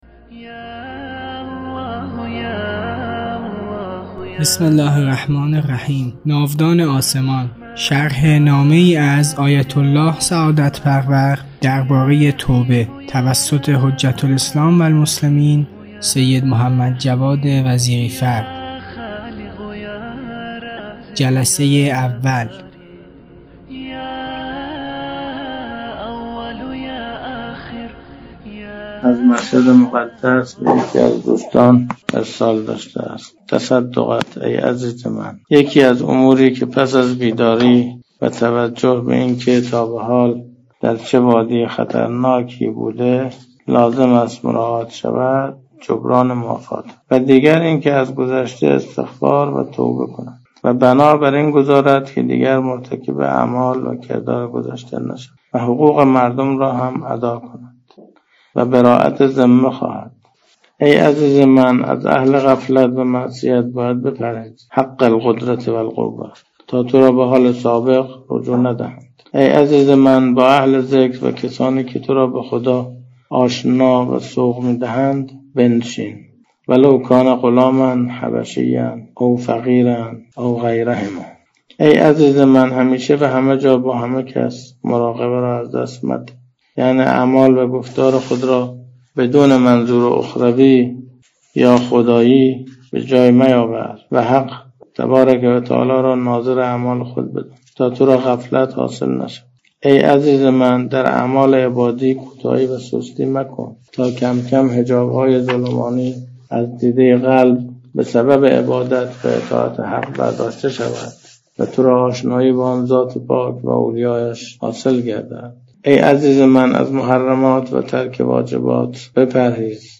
در این جلسه، موعظه‌ای تکان‌دهنده درباره مفهوم واقعی "بیداری معنوی" ارائه می‌شود.